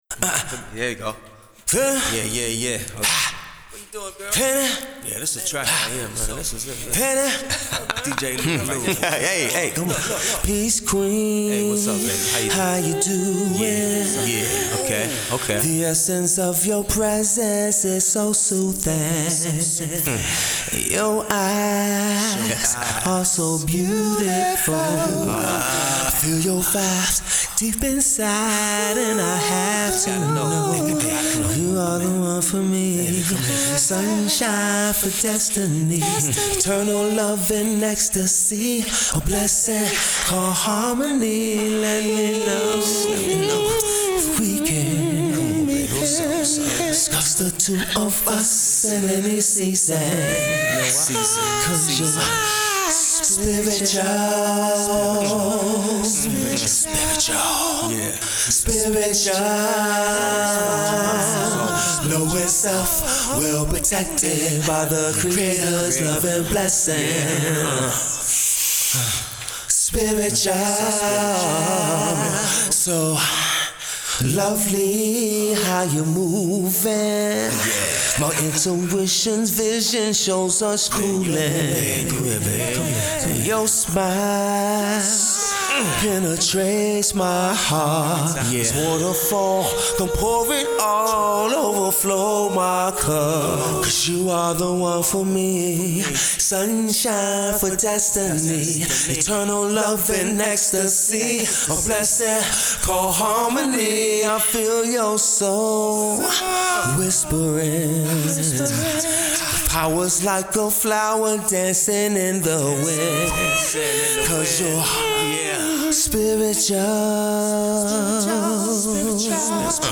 New Neo Soul R&B Heat for the ladies